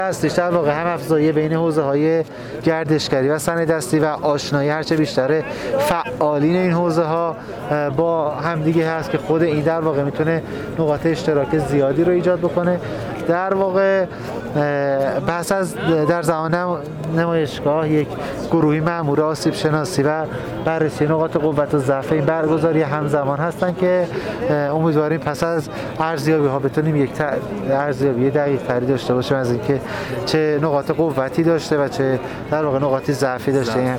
شالبافیان در گفت‌وگو با ایکنا تببین کرد؛